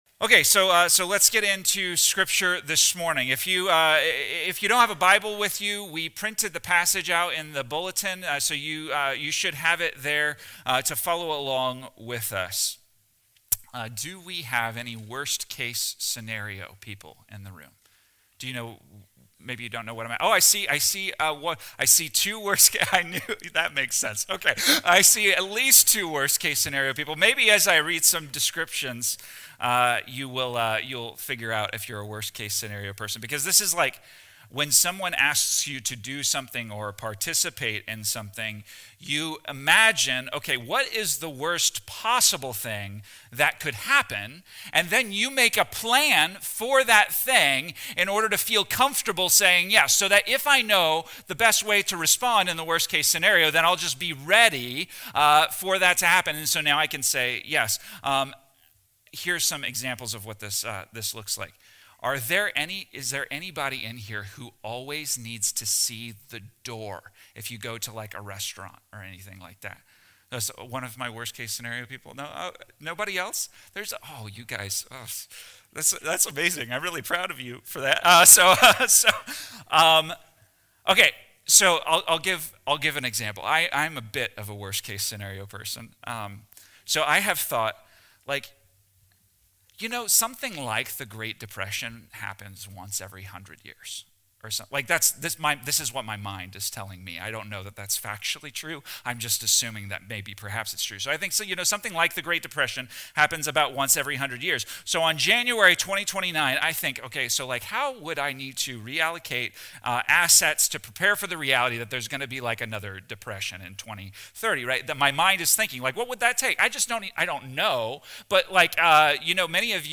This Advent message dives into what "God with Us" really means, especially when we're dealing with anxiety and uncertainty. Using Isaiah 35 and Luke 7:18-23, it highlights how Jesus, as God among us, shows up to fulfill promises and bring hope, even when life feels overwhelming. The sermon looks at how Jesus’ miracles, like healing the blind and comforting the brokenhearted, are proof that God is at work and that evil has an expiration date.